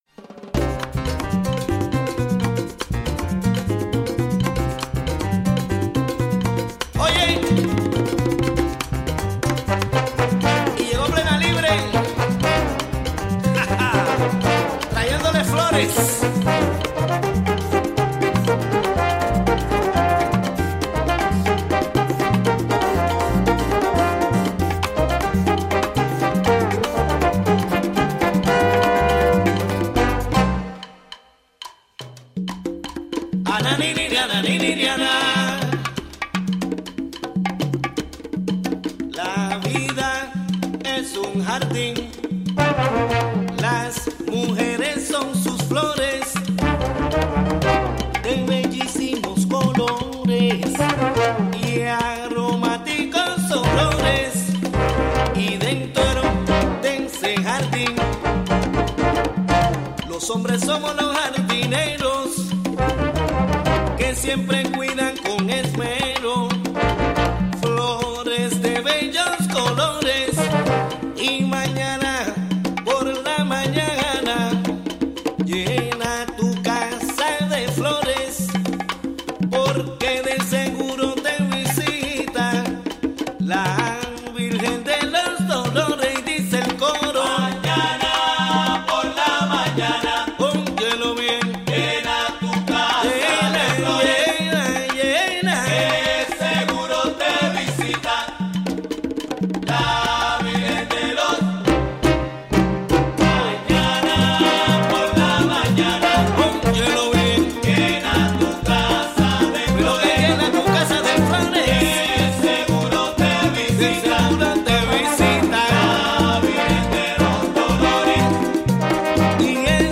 11am Un programa imperdible con noticias, entrevistas,...